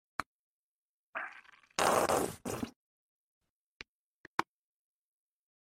Tiếng xịt sốt gia vị, Tương cà, Mù tạt, sốt BBQ, sốt salad… (Mẫu số 2)
Thể loại: Tiếng ăn uống
Description: Hiệu ứng âm thanh Tiếng xịt sốt gia vị, Tiếng xịt tương cà, Tiếng xịt mù tạt, Tiếng xịt sốt BBQ, Tiếng xịt sốt salad (Mẫu số 2) tái hiện chân thực âm thanh khi bóp chai sốt, rưới lên món ăn như burger, xúc xích hay salad. Âm thanh xì, xịt, phụt, lép bép sinh động, gợi cảm giác tươi ngon và hấp dẫn..
tieng-xit-sot-gia-vi-tuong-ca-mu-tat-sot-bbq-sot-salad-mau-so-2-www_tiengdong_com.mp3